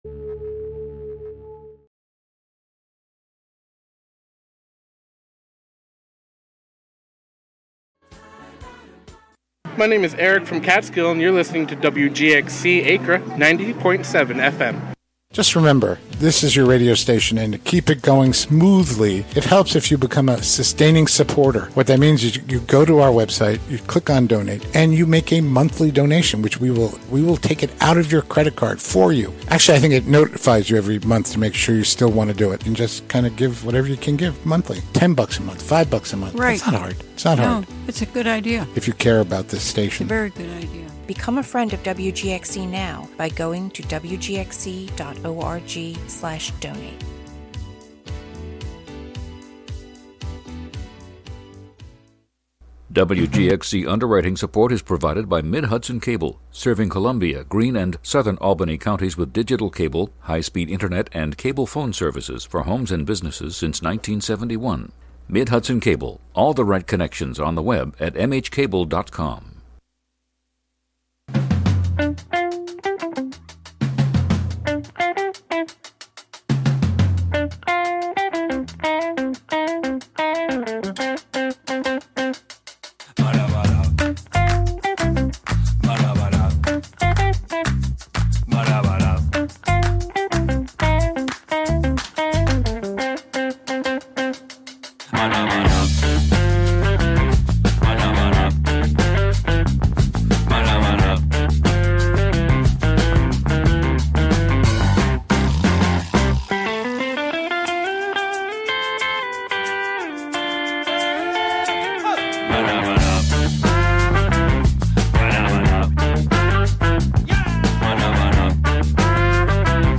The WGXC Morning Show is a radio magazine show featuring local news, interviews with community leaders and personalities, a rundown of public meetings, local and regional events, with weather updates, and more about and for the community.